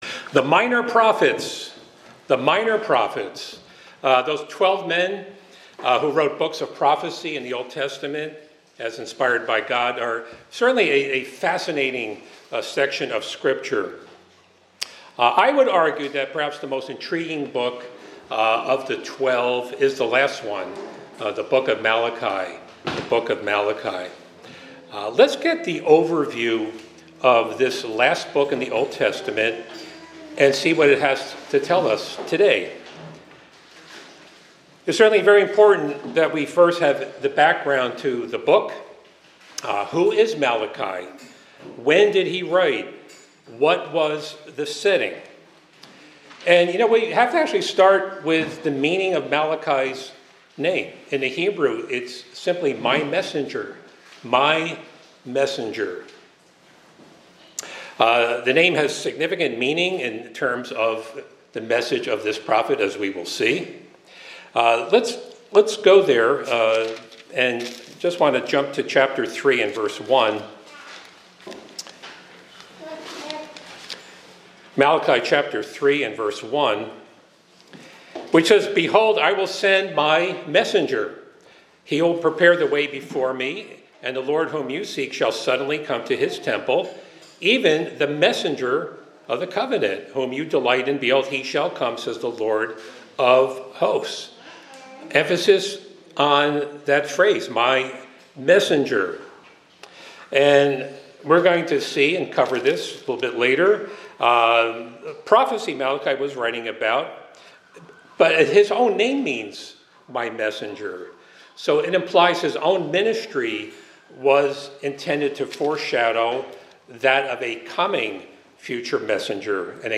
This sermon explores the profound messages and prophecies found in the Book of Malachi, the last of the twelve minor prophets in the Old Testament.